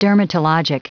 Prononciation du mot dermatologic en anglais (fichier audio)
Prononciation du mot : dermatologic